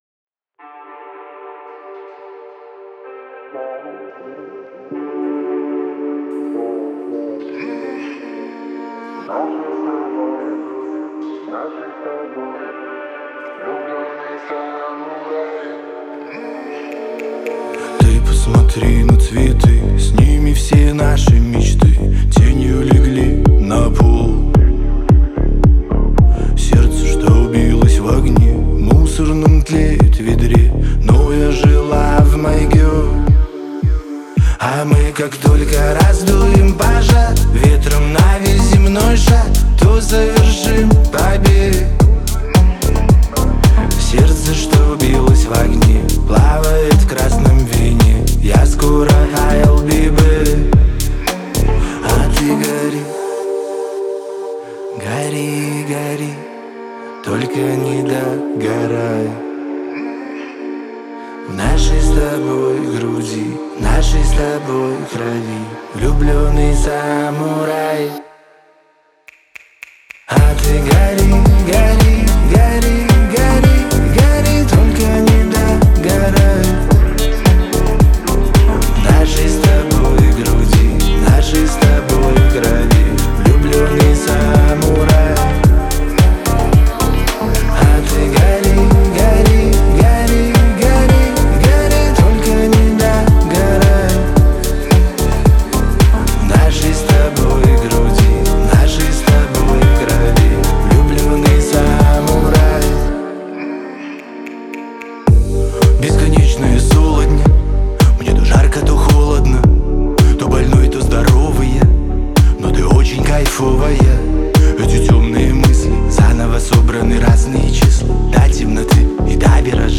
это яркая и эмоциональная песня в жанре поп-рок